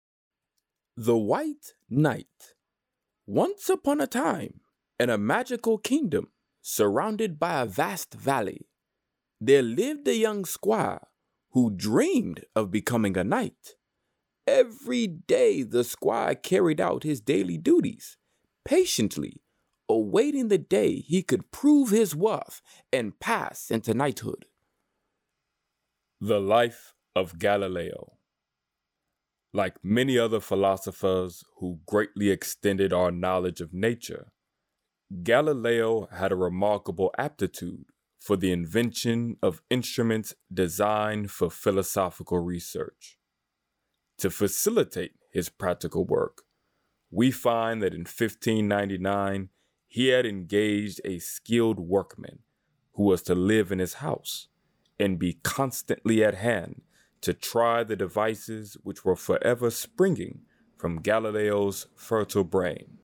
Audiobooks
AudioBook-Demo-Reel-1.mp3